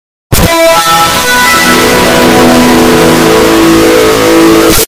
Windows 7 earrape
windows-7-earrape.mp3